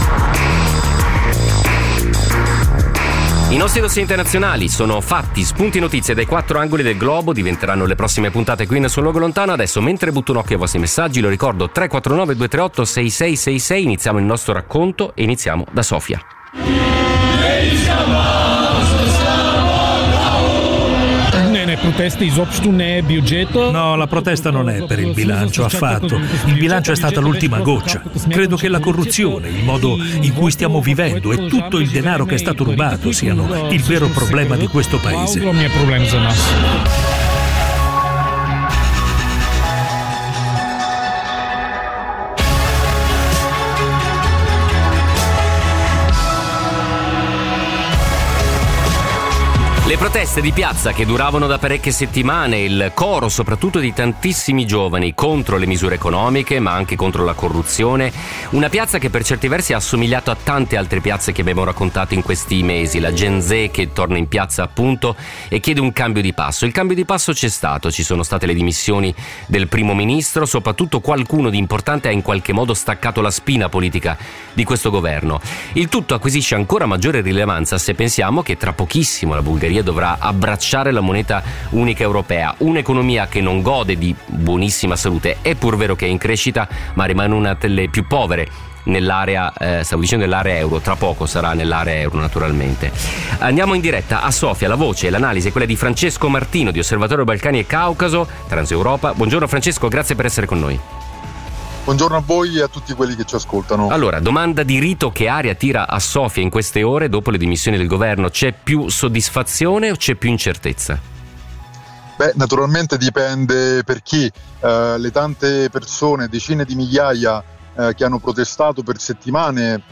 in diretta da Sofia